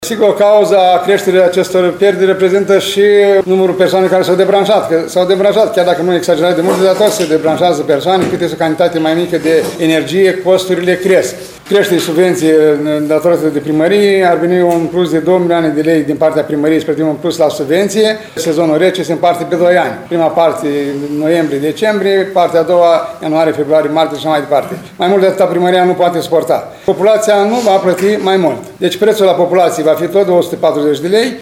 Primarul ION LUNGU a declarat astăzi că efortul financiar al municipalității se ridică la 2 milioane lei pe sezon rece, în condițiile în care tariful de distribuție a energiei termice nu a mai fost actualizat de 5 ani.